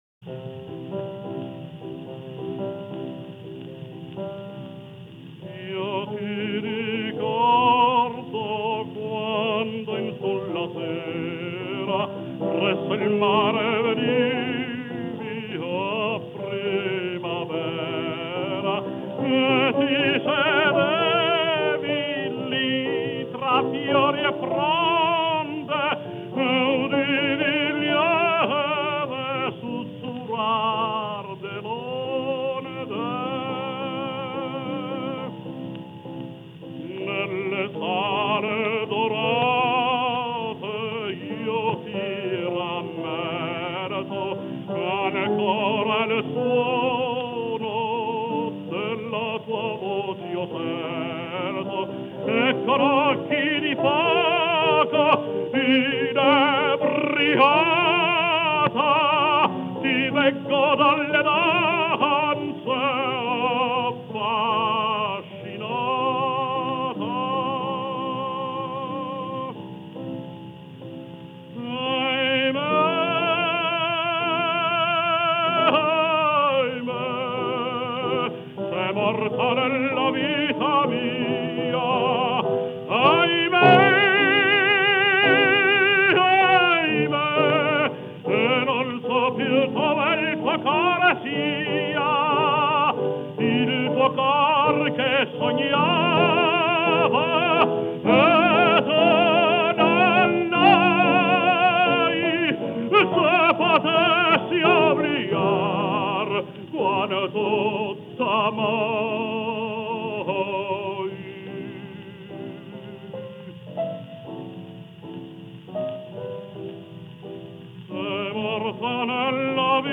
Жанр: Vocal
piano